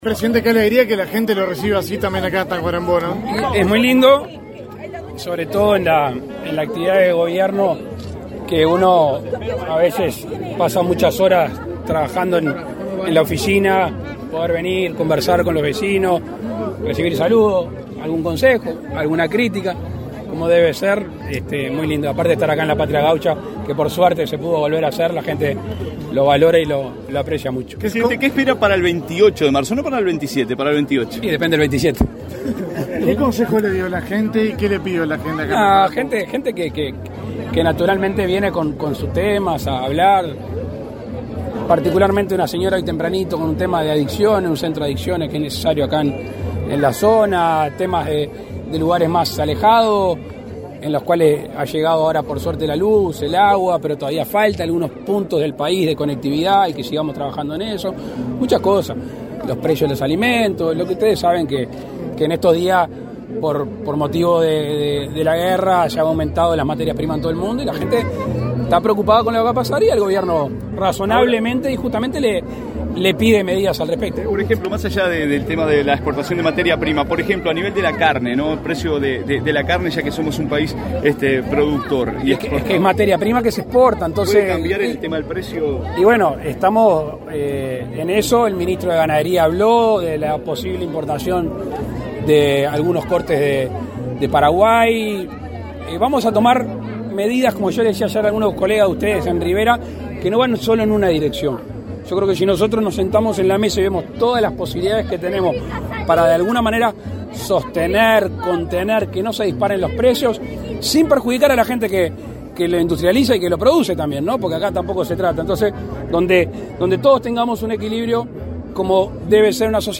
Declaraciones a la prensa del presidente de la República, Luis Lacalle Pou, en Tacuarembó
En ese contexto, efectuó declaraciones a la prensa.